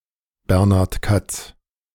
Sir Bernard Katz, FRS[1] (German pronunciation: [ˈbɛʁnaʁt kat͡s]